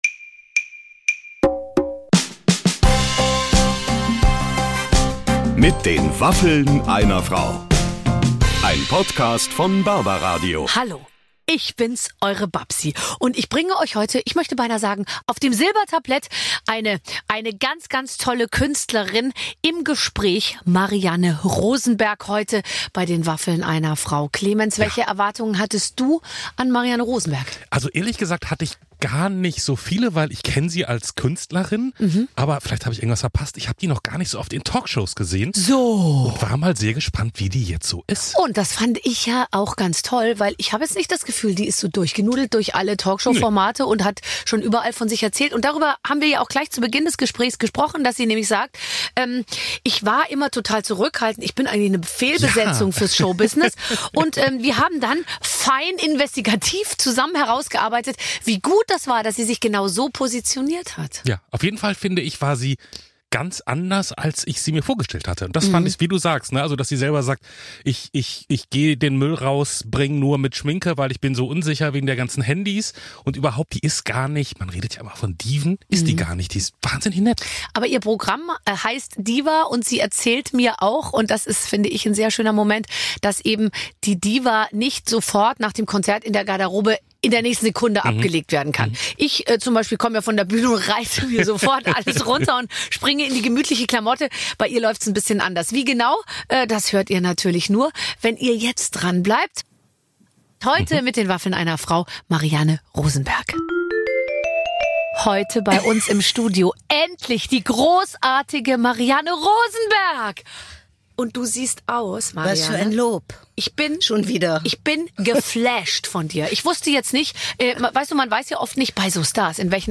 Marianne Rosenberg hat es auf unseren Studiohocker geschafft und eifrig Fragen beantwortet. Wir haben unter anderem erfahren, wie wichtig der Glamour für ihre Kunstfigur ist, wieso sie das Haus niemals ungeschminkt verlässt und warum sie keine Horrorfilme mehr mag.